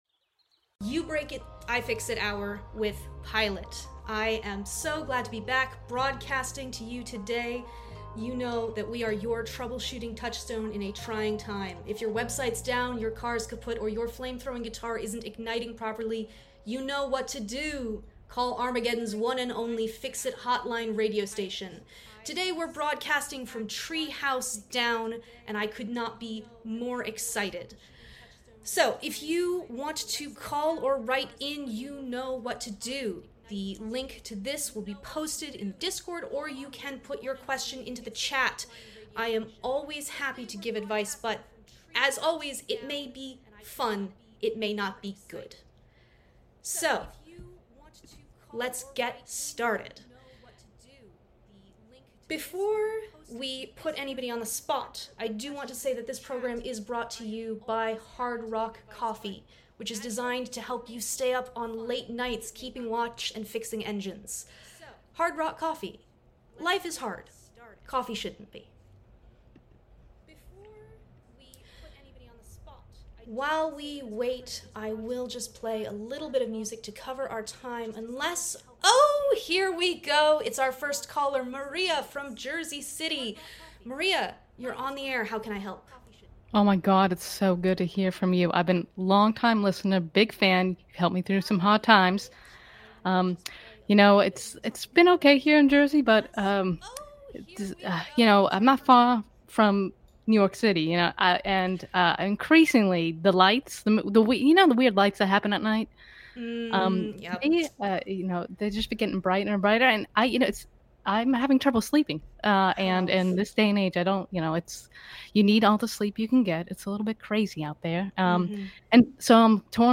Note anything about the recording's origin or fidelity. Long Time Listener, Last Time Caller is a roleplaying game about a radio station broadcasting at the end of the world. This session, which will happen remotely and run about an hour, takes inspiration from Car Talk and Mad Max — just don’t expect any realistic advice.